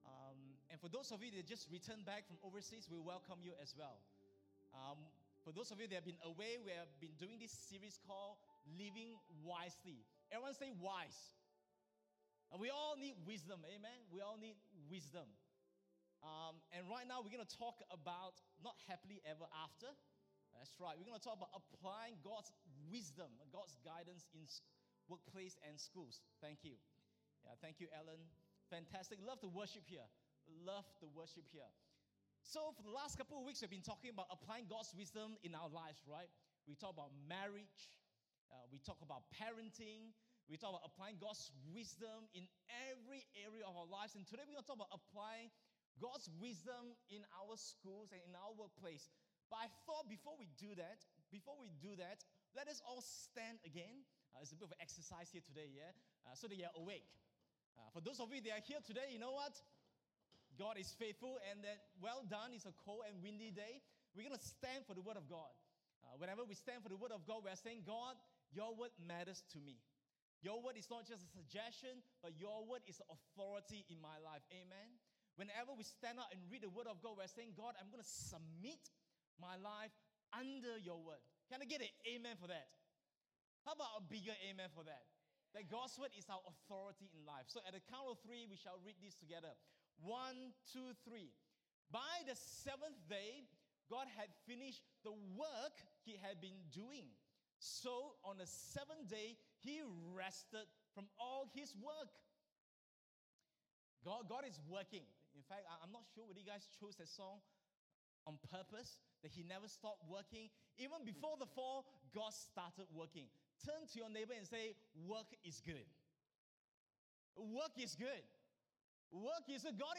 English Worship Service - 28th May 2023
Sermon Notes